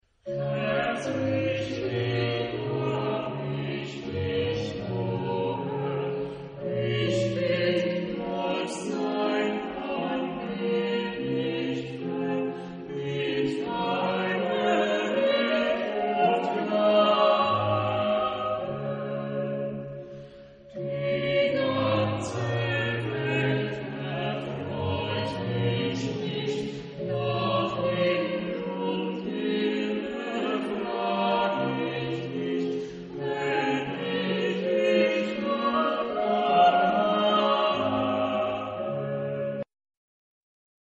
Genre-Style-Forme : Sacré ; Choral
Type de choeur : SATB  (4 voix mixtes )
Tonalité : ré majeur